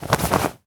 foley_object_push_pull_move_01.wav